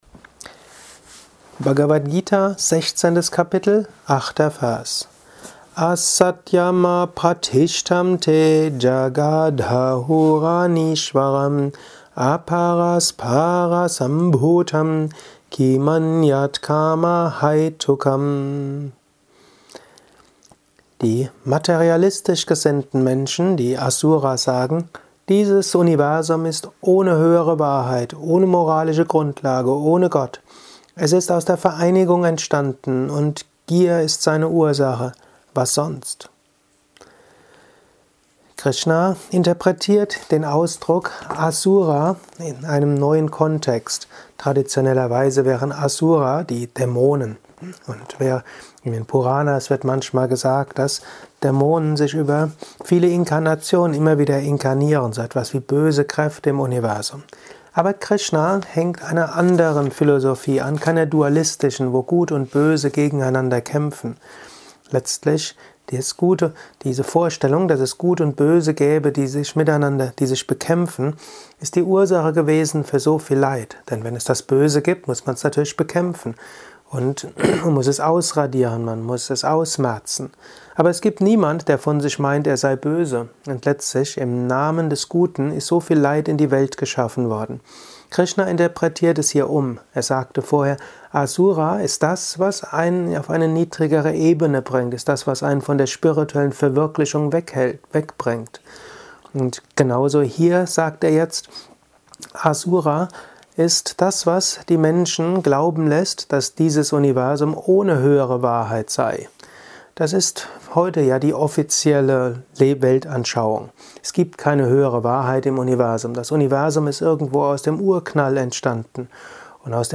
Höre einen kurzen Beitrag zur Bhagavad Gita Kapitel XVI Vers 8: Wahrheit ist die Grundlage des Universums. Dieses Universum lebt durch die höhere Wirklichkeit.